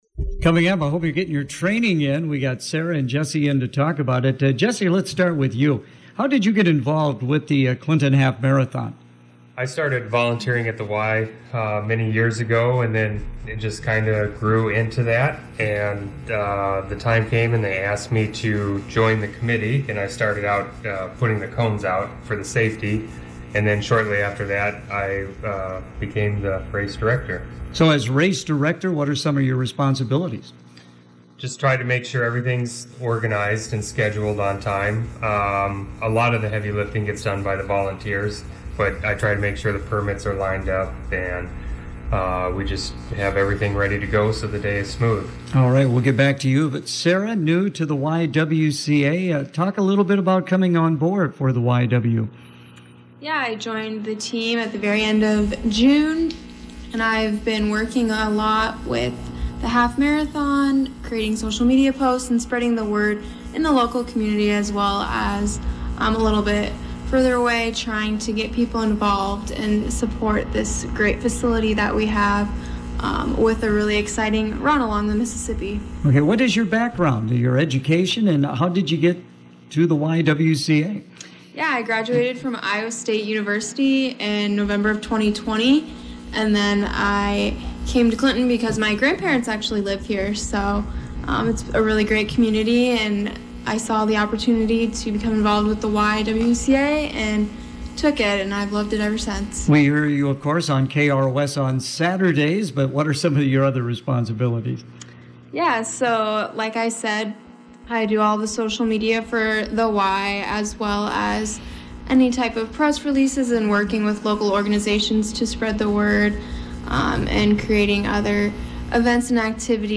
If you missed the interview on the air, you can listen here by clicking play on the audio player below.